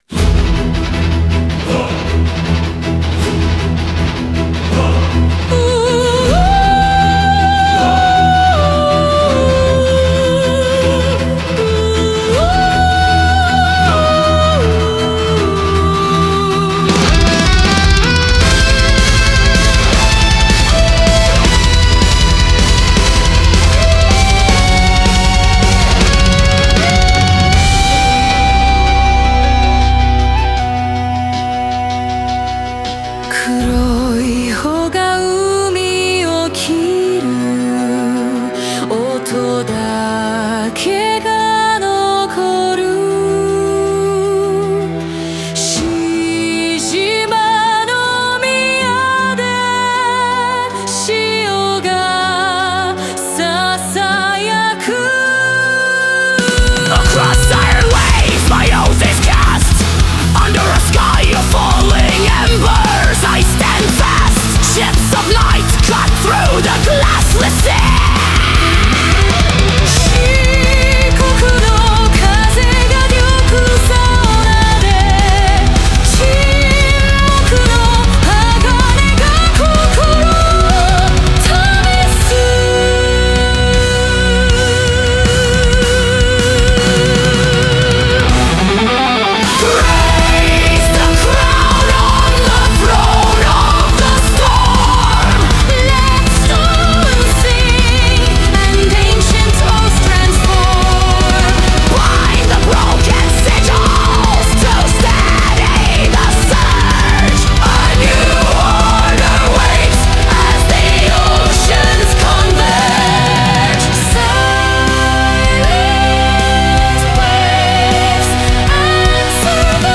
Metal Ballad